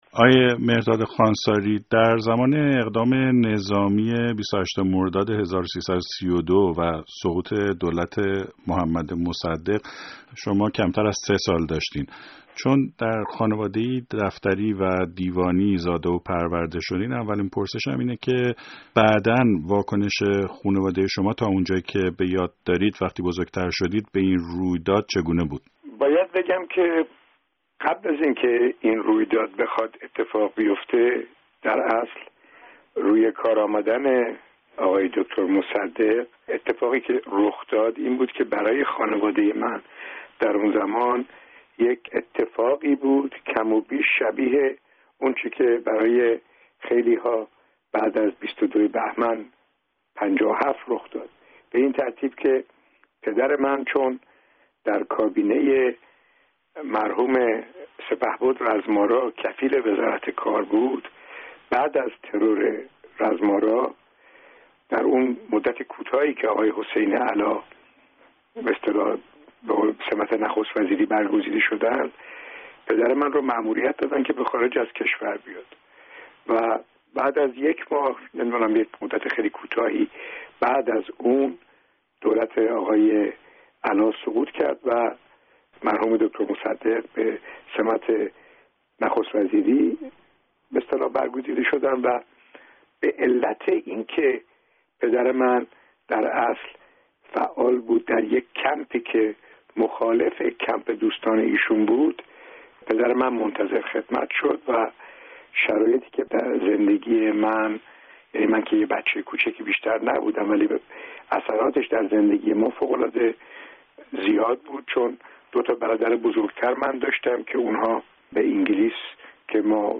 Embed share ۲۸ مرداد و سقوط دولت مصدق در گفت‌وگو